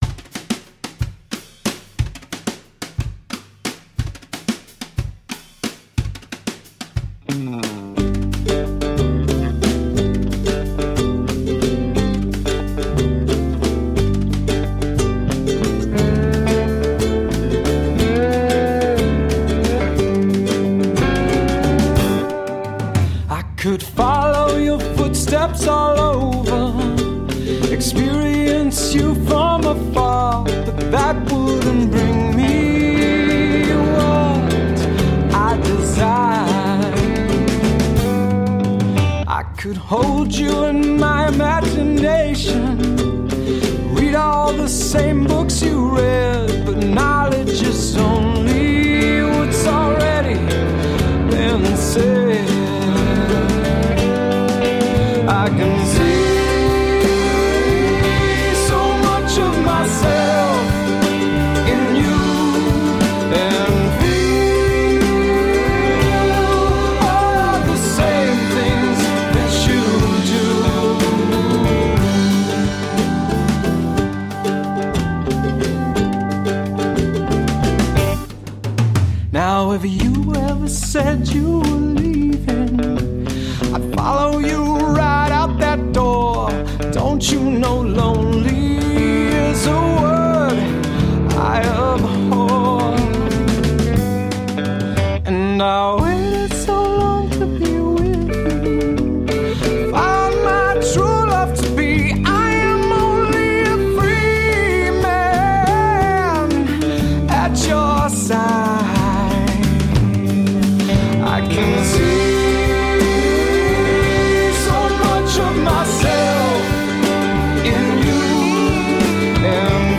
Heart on our sleeves rock and roll..
Tagged as: Alt Rock, Rock, Classic rock